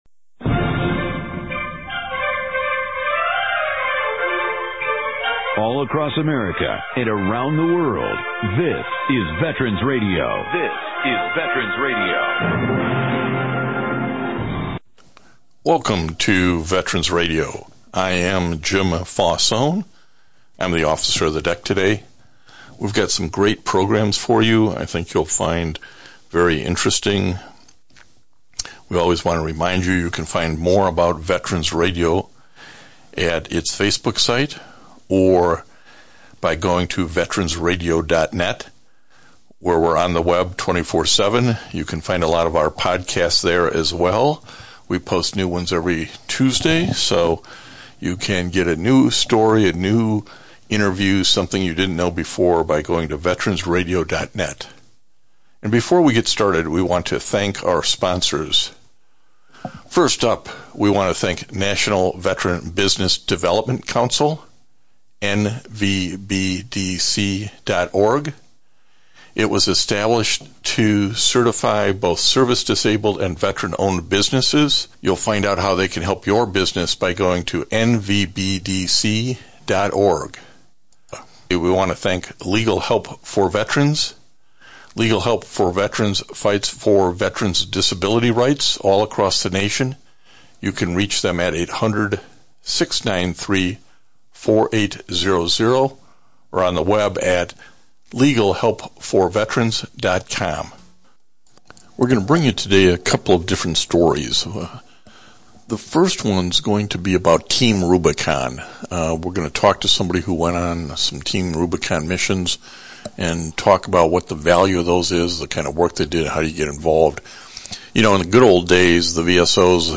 This week’s one hour radio broadcast